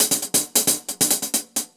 Index of /musicradar/ultimate-hihat-samples/135bpm
UHH_AcoustiHatC_135-05.wav